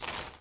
coins.ogg